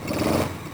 accelerate.wav